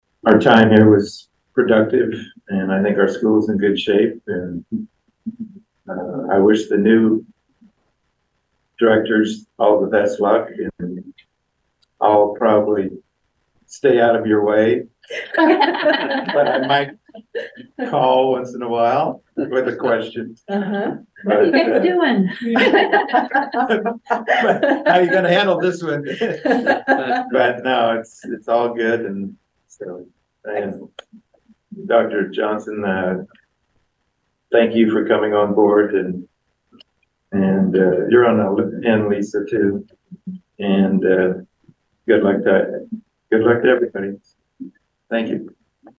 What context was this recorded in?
(Atlantic) The Atlantic School Board held a final meeting of the retiring Board and conducted an organizational meeting with the new Board on Wednesday evening.